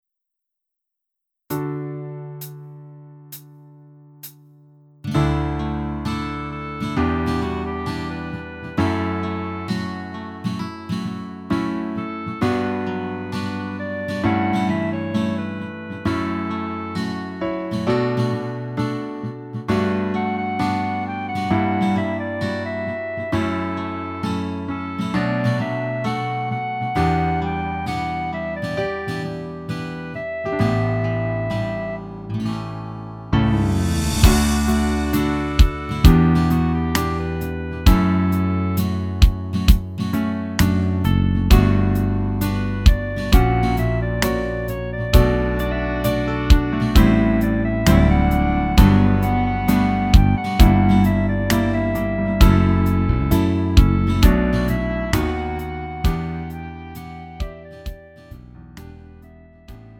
음정 -1키 4:12
장르 가요 구분